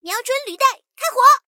SU-76开火语音2.OGG